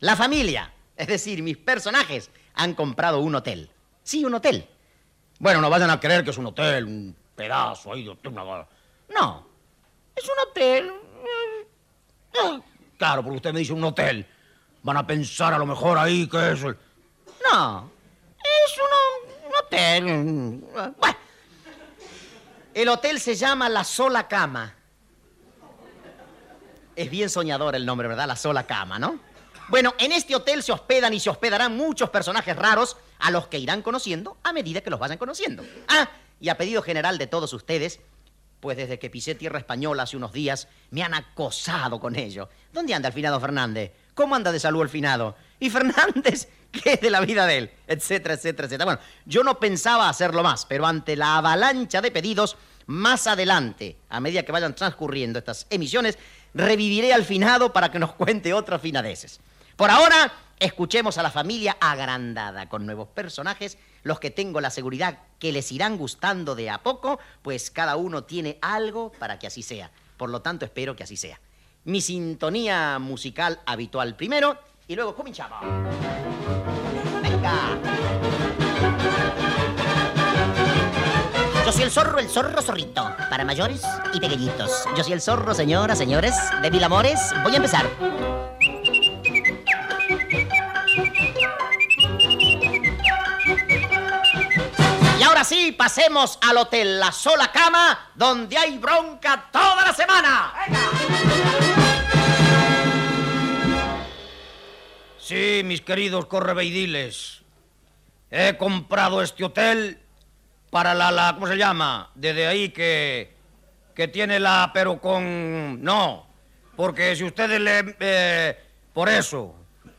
Presentació, cançó cantada i xiulada de sintnonia, Don Clarete ha comprat l'Hotel La Sola Cama
Entreteniment